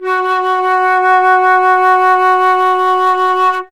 51c-flt16-F#3.wav